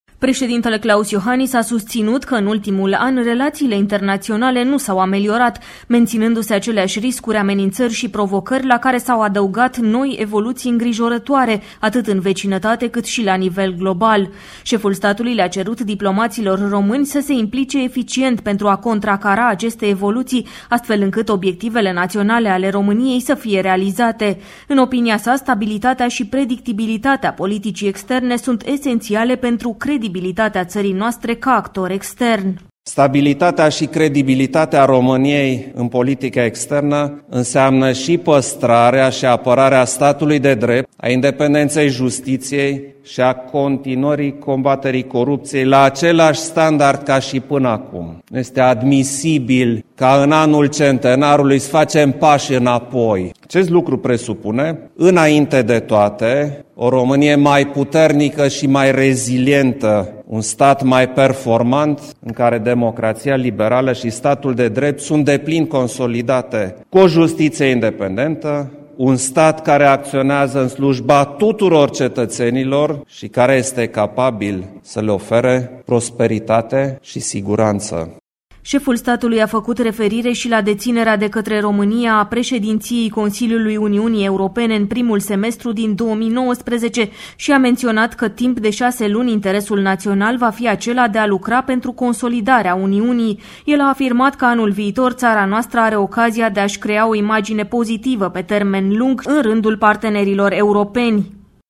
Klaus Iohannis a susținut un discurs cu prilejul Reuniunii anuale a Diplomaţiei Române